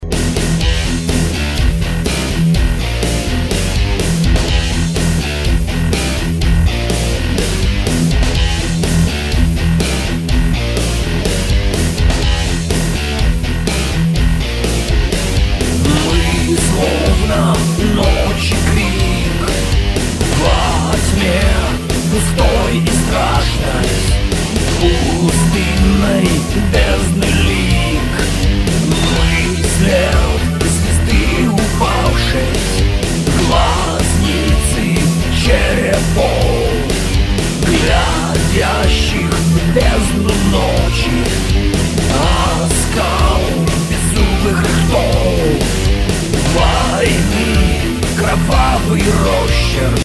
Рок (320)